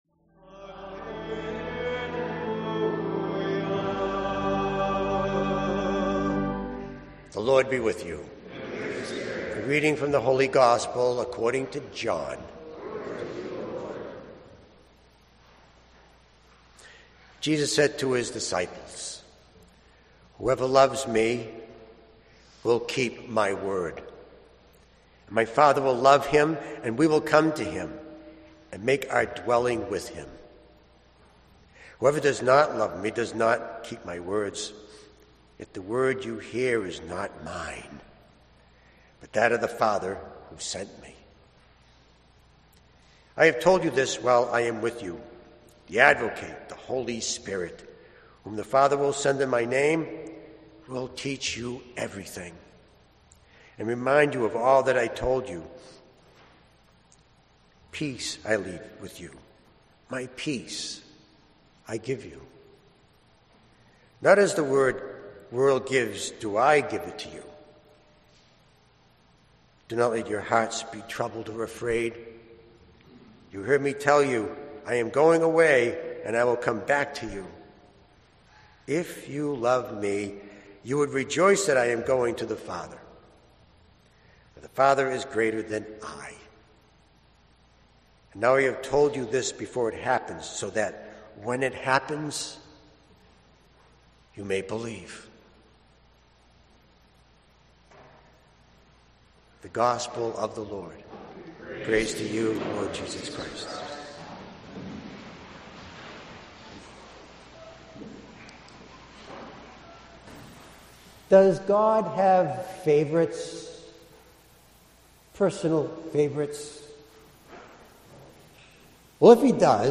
Gospel Homily for Sixth Sunday of Easter (2025) 6:12